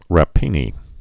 (ră-pēnē)